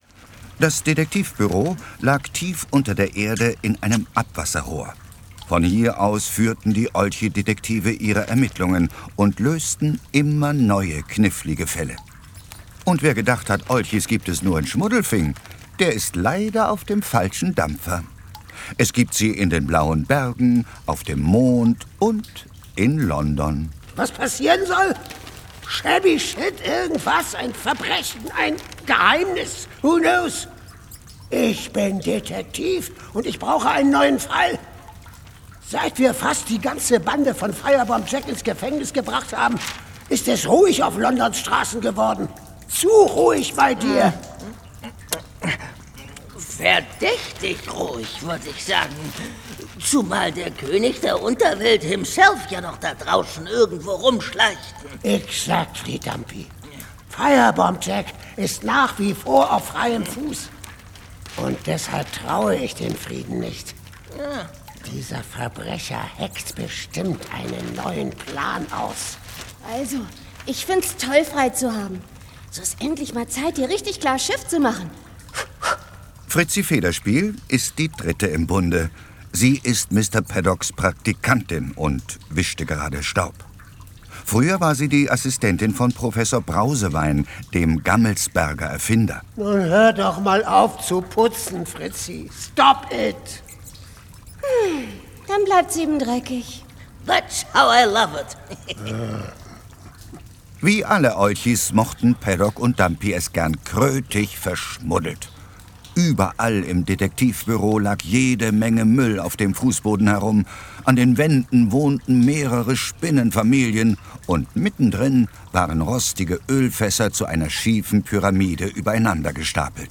Ravensburger Olchi-Detektive 3 - Löwenalarm ✔ tiptoi® Hörbuch ab 6 Jahren ✔ Jetzt online herunterladen!